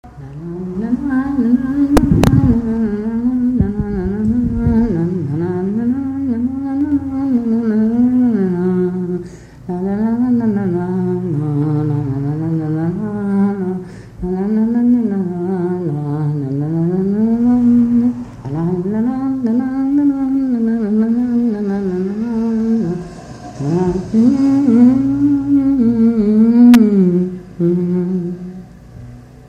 Mémoires et Patrimoines vivants - RaddO est une base de données d'archives iconographiques et sonores.
Figure de quadrille
Pièce musicale inédite